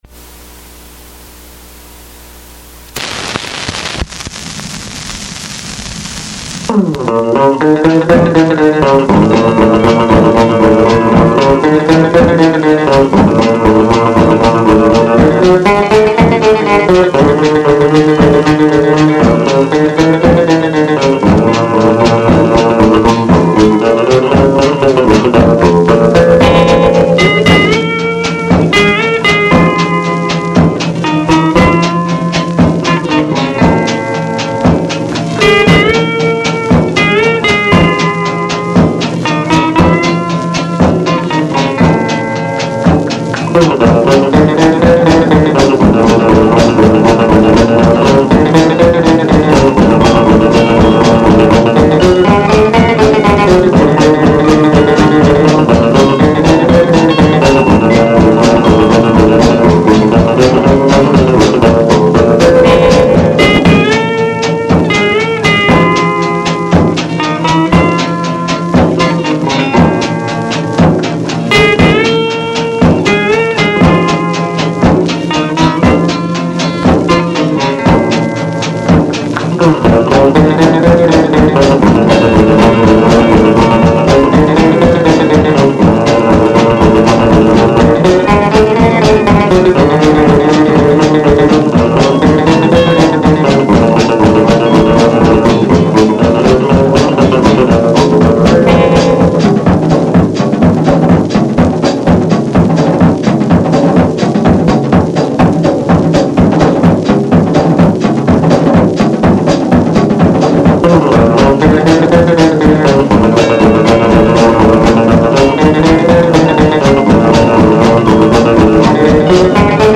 scratchy but listenable